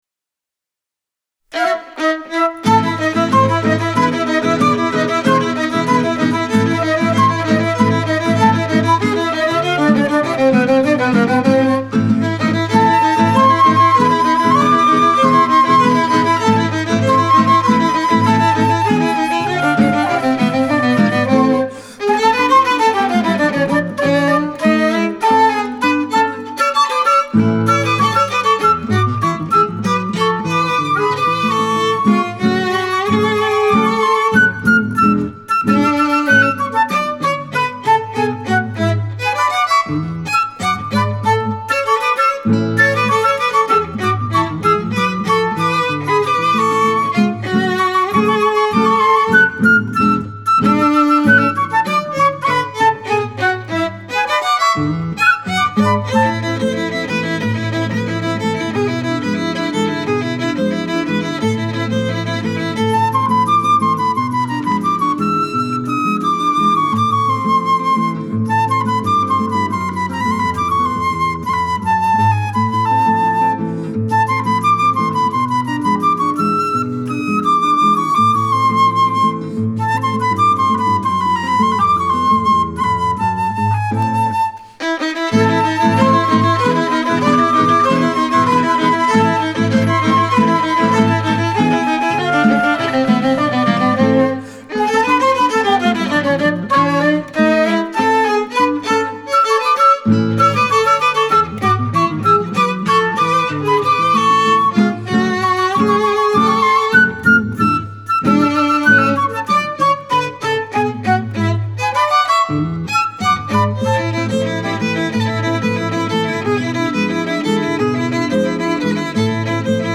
registrato dal vivo: Verona, dicembre 2012